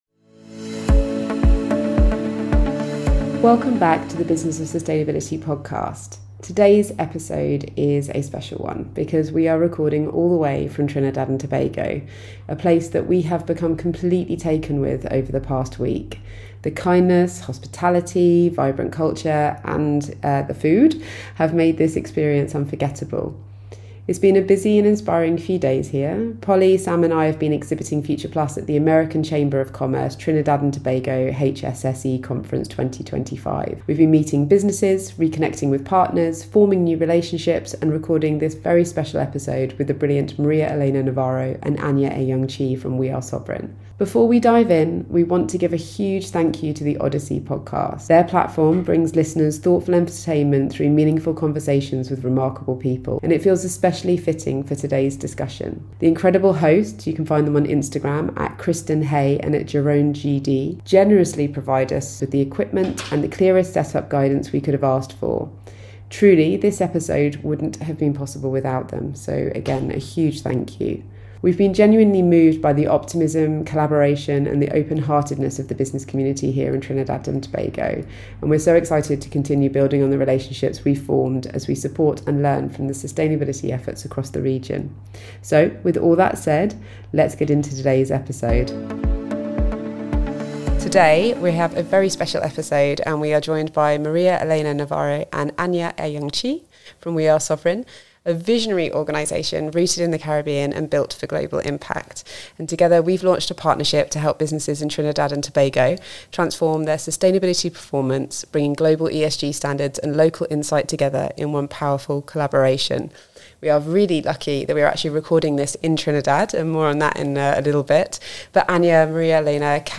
In this special episode recorded on location in Trinidad & Tobago